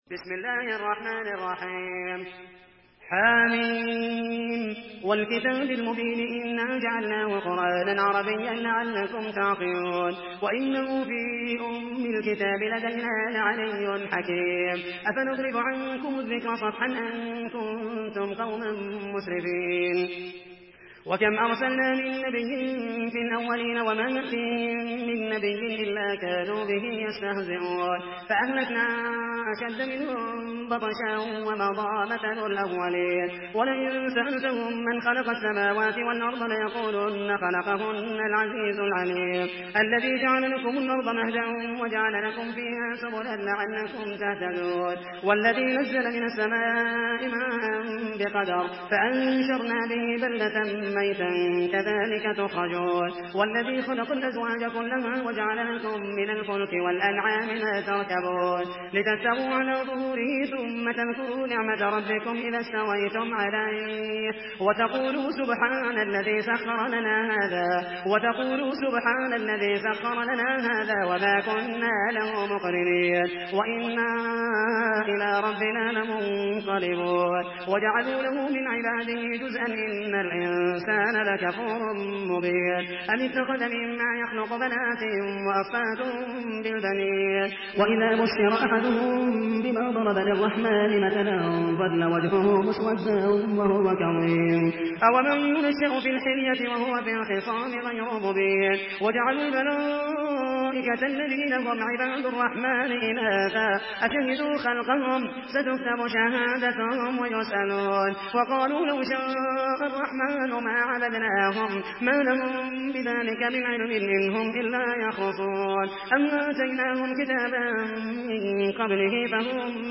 Surah আয-যুখরুফ MP3 by Muhammed al Mohaisany in Hafs An Asim narration.
Murattal Hafs An Asim